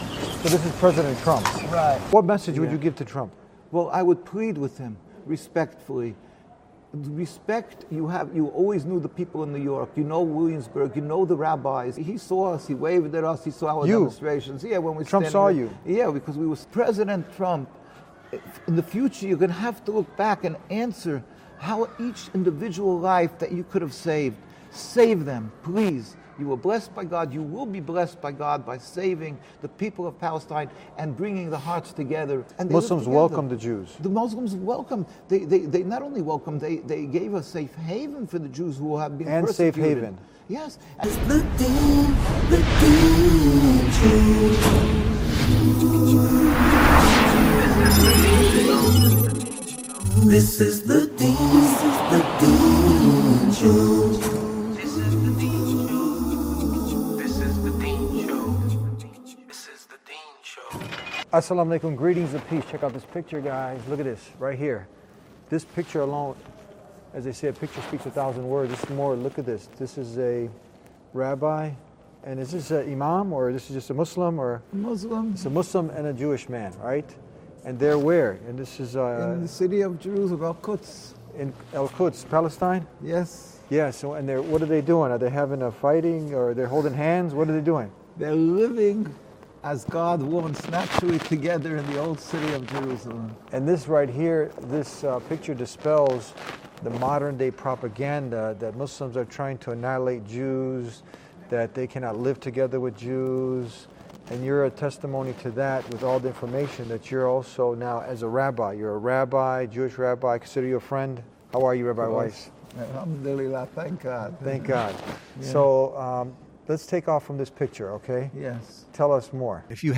In this enlightening episode of The Deen Show, the Jewish Rabbi delivers a powerful message to President Trump, urging him respectfully to consider the lives...